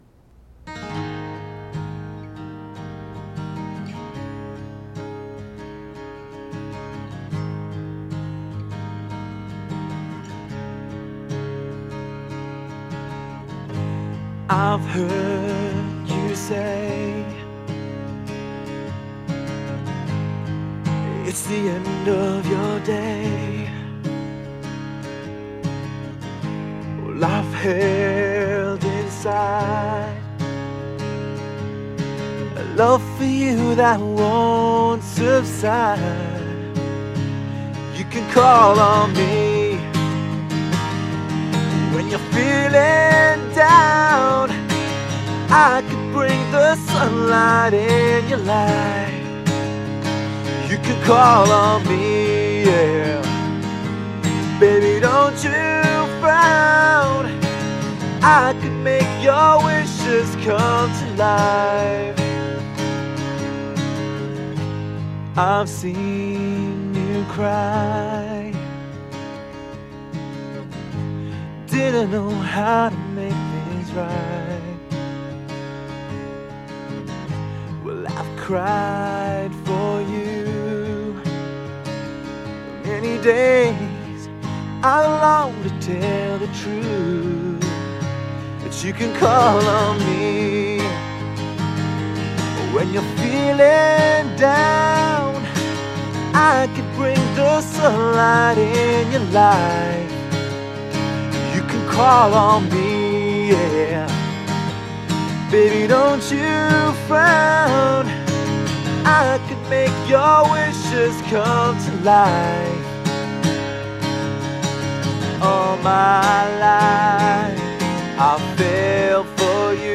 آهنگسازی - Composing